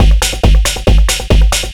DS 138-BPM B5.wav